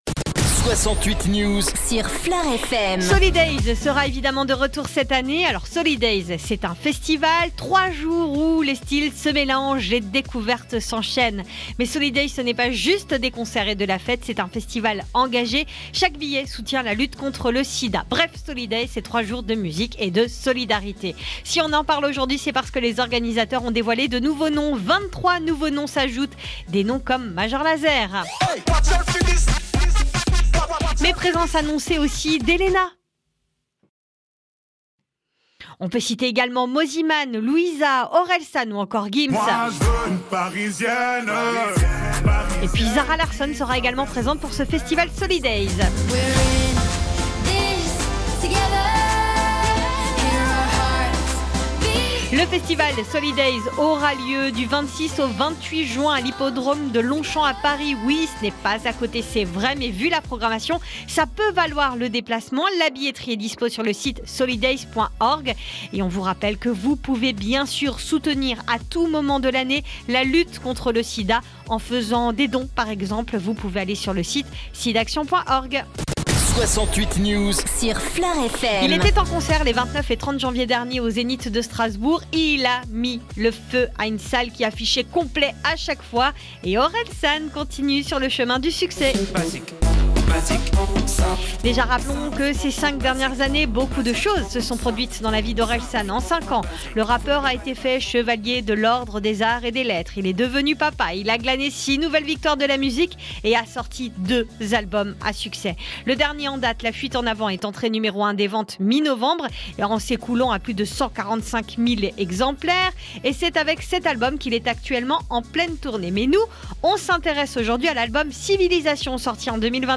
FLOR FM : Réécoutez les flash infos et les différentes chroniques de votre radio⬦